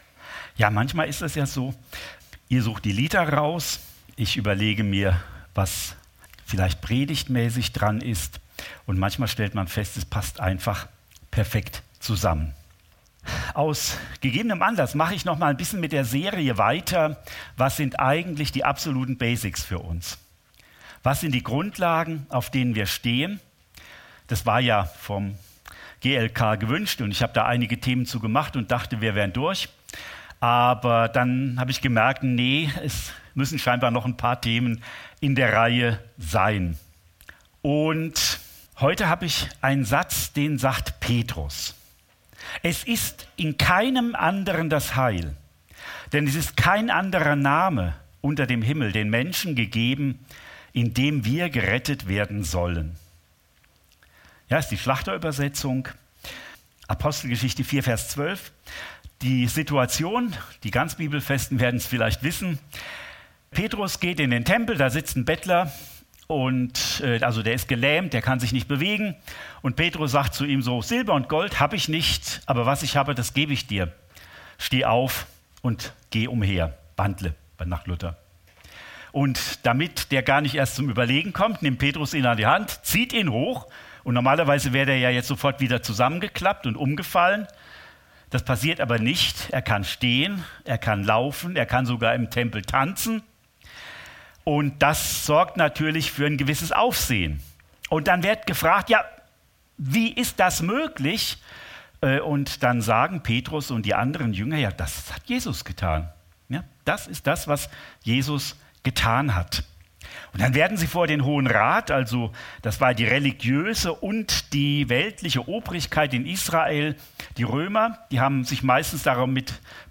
Predigt vom 20. Oktober 2024 – Süddeutsche Gemeinschaft Künzelsau